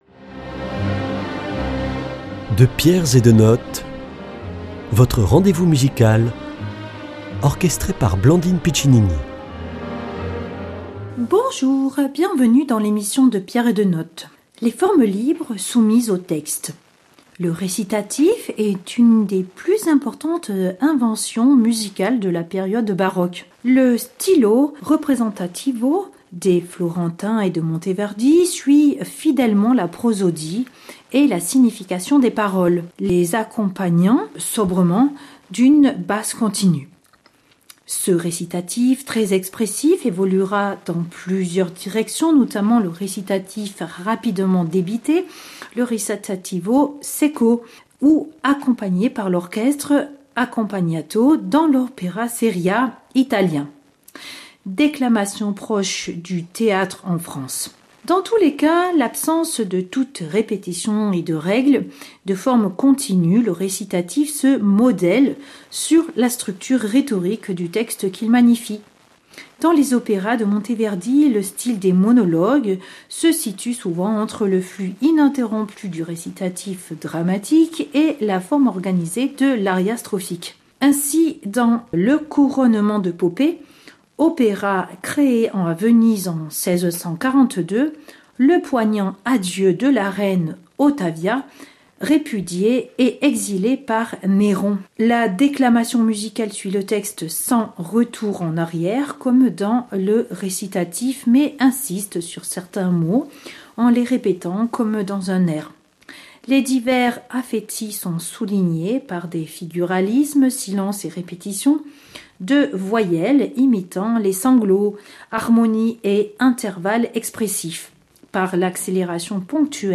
Il s’agit de découvrir les formes libres soumises au texte dans la musique baroque. Extrait du Couronneent de Poppée, de Monteverdi acte 3 scène 7, interprété par le concerto vocale, René Jacobs, puis l’halleluia extrait du Messie de Heandel interprété par les arts Florissants et enfin Louis Couperin, le prélude non mesuré, qui montre le recit et les préludes instrumentaux.